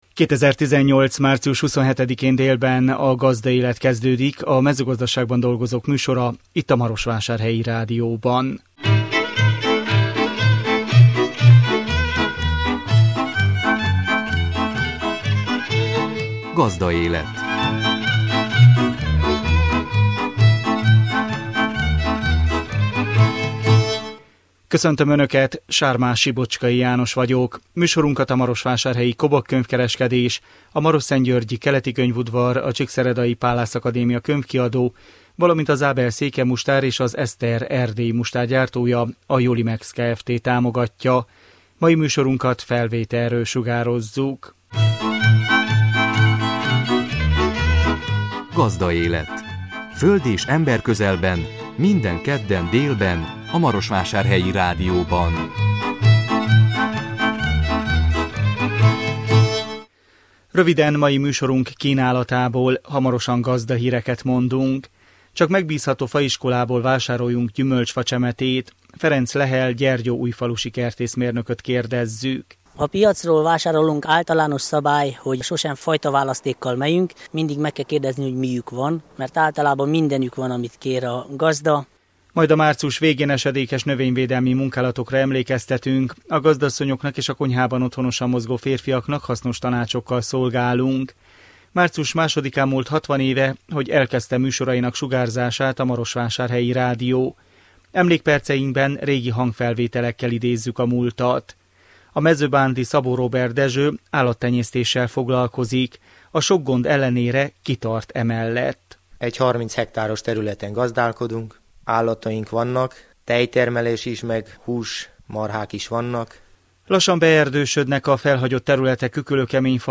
Emlékperceinkben régi hangfelvételekkel idézzük a múltat.